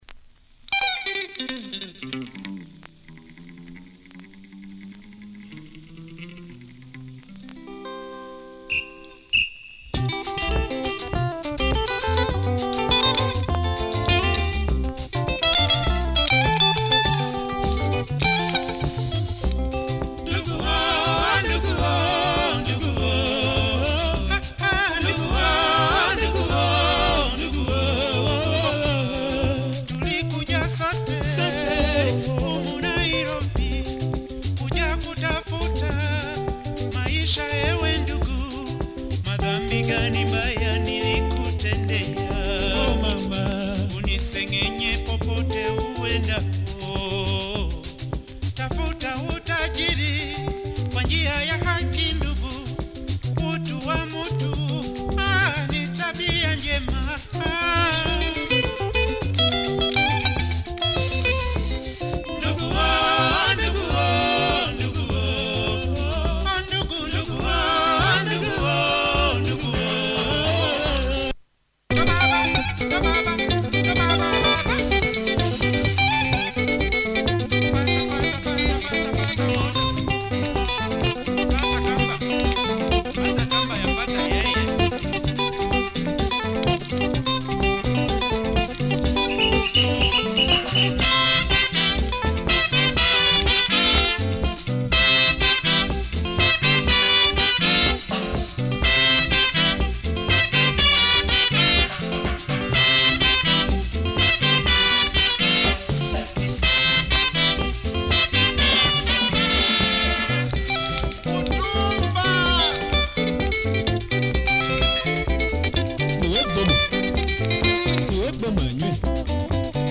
lingala